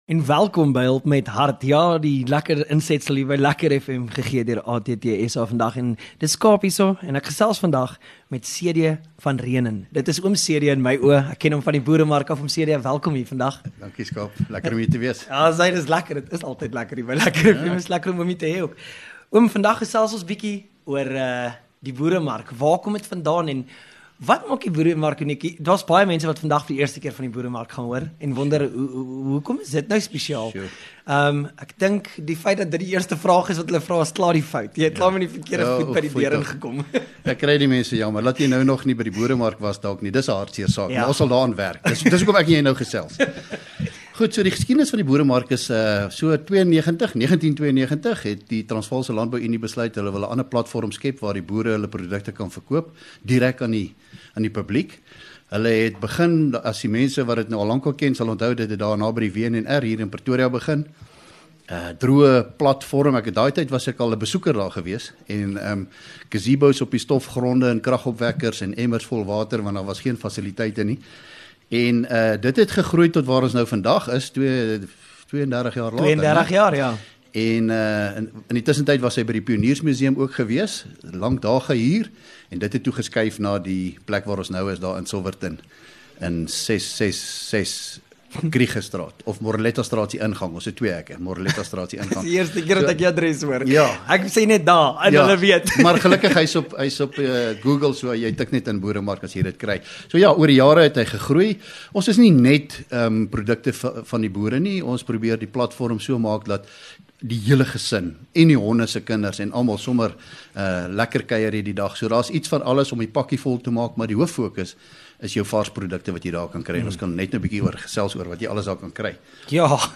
LEKKER FM | Onderhoude 22 Jan Hulp met Hart - TLU Boeremark